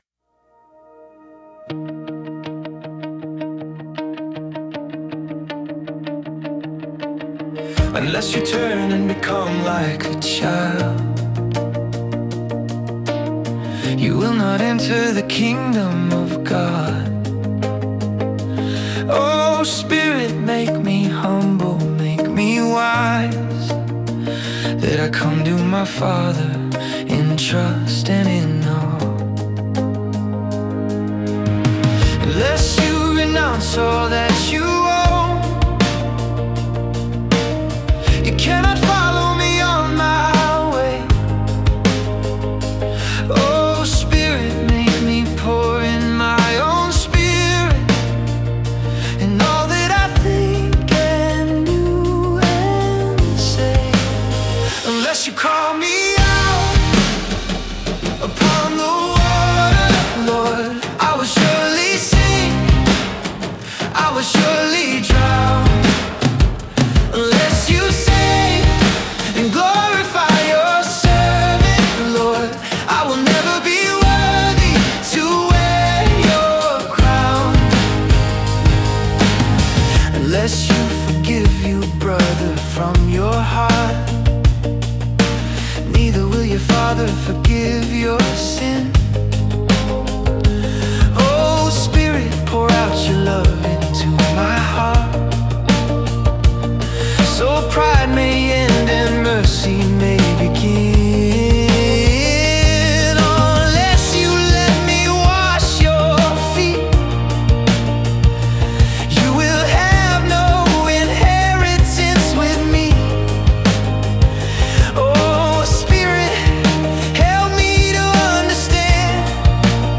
Unless (Indie Rock, about discipleship and grace)